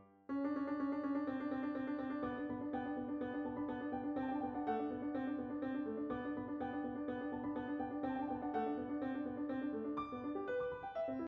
右手のメロディの背景でさりげなく左手の下の音が遷移しますが、赤枠の箇所は下の音が全て異なっています。